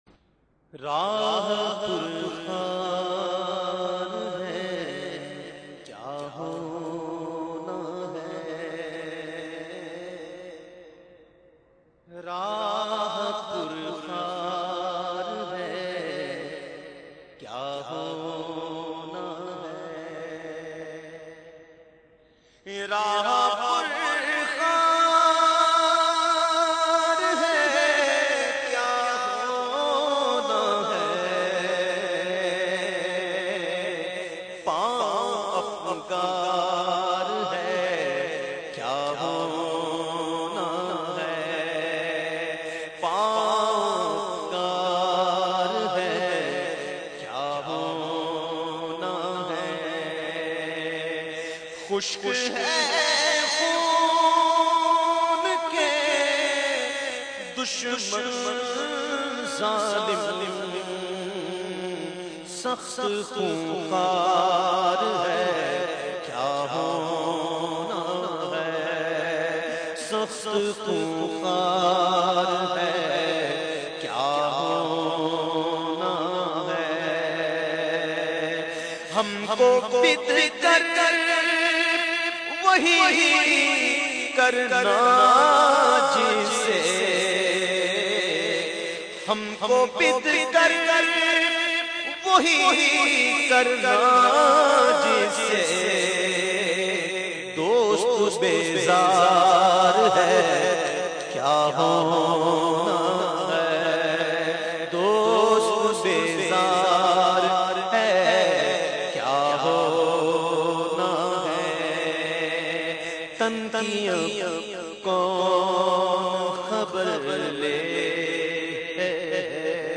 Naat Sharif